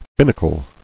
[bin·na·cle]